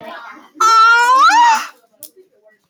Sus Man Noise Botão de Som